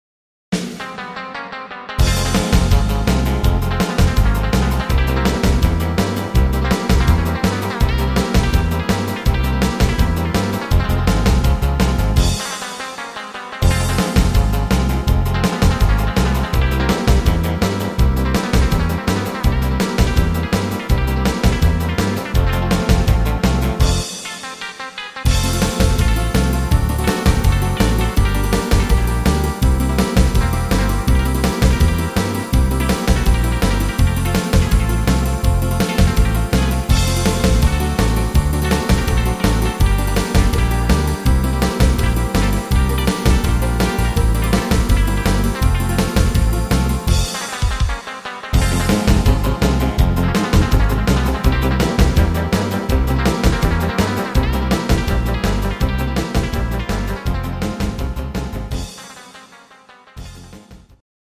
instrumental Gitarre